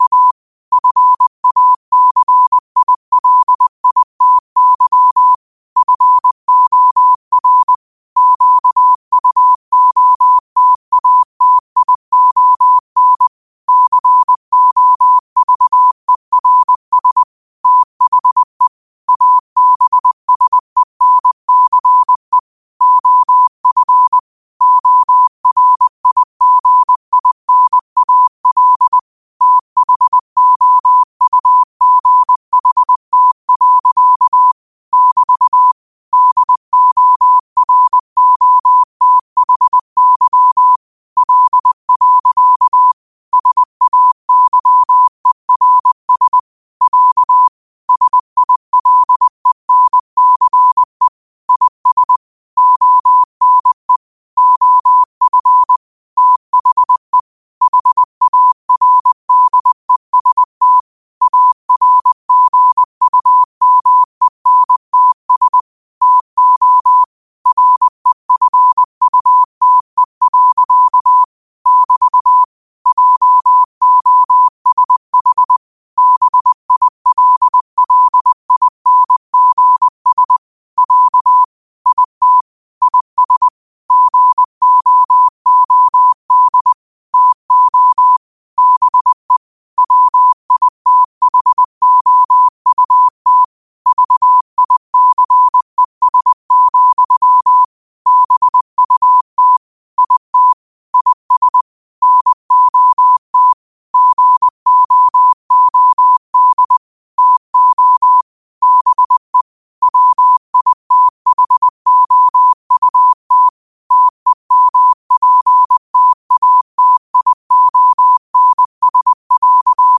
New quotes every day in morse code at 20 Words per minute.